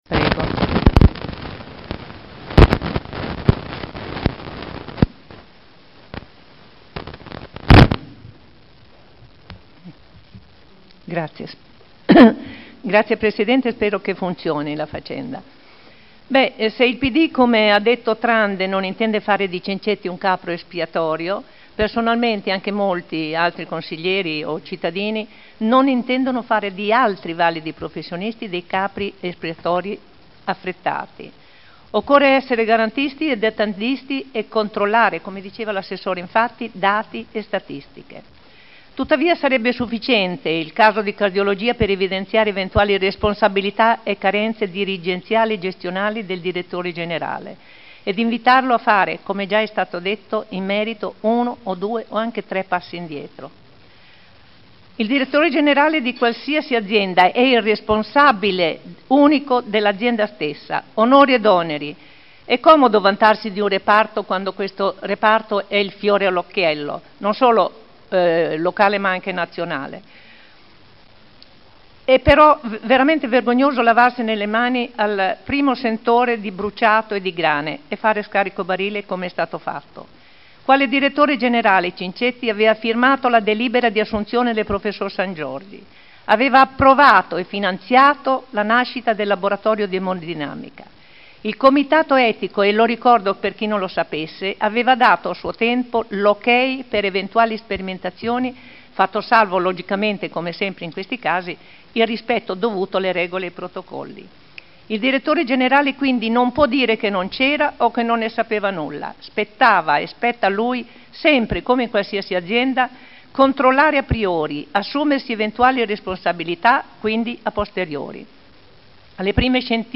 Olga Vecchi — Sito Audio Consiglio Comunale
Seduta del 09/05/2011.